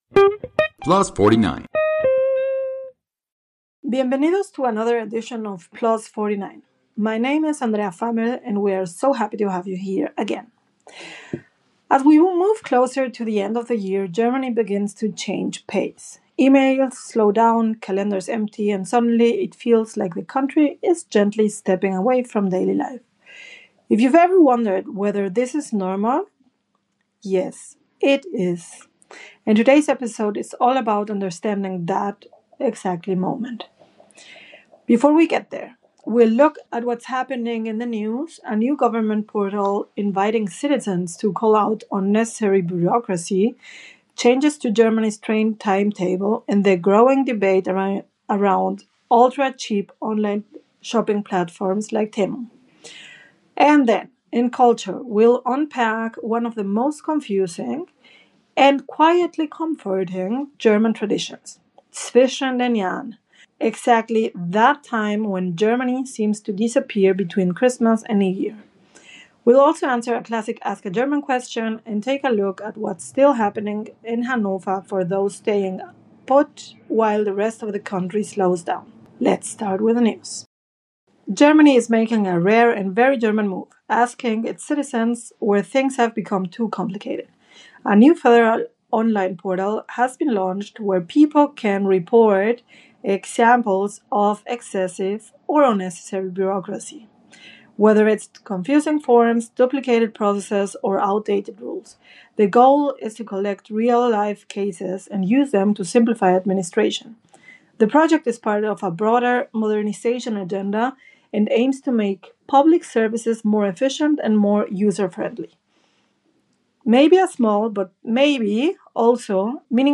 A grounded conversation about ambition without burnout, structure without rigidity, and why sometimes the most radical move is knowing when to sell, rest, and begin again.